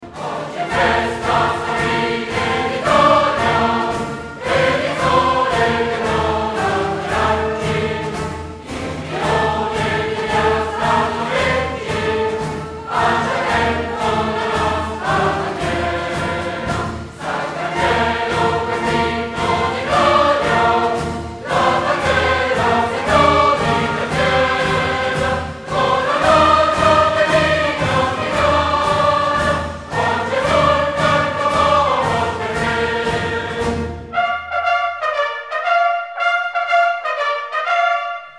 suoneria per smartphone